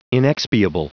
Prononciation du mot inexpiable en anglais (fichier audio)
Prononciation du mot : inexpiable